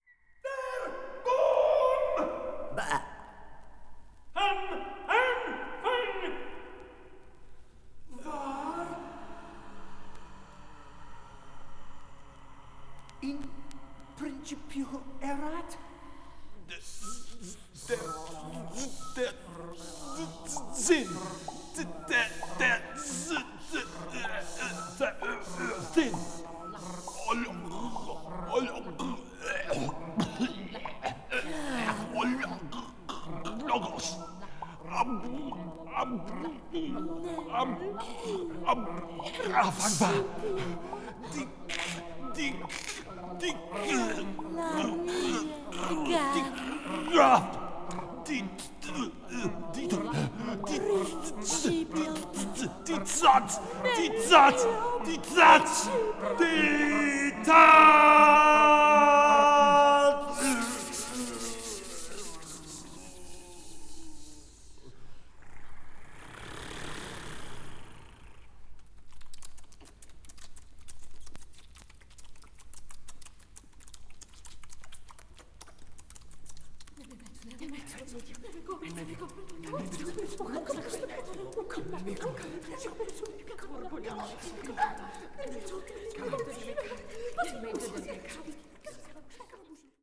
a British vocal group
and known for their experimental electro-acoustic works.
20th century   avant-garde   contemporary   experimental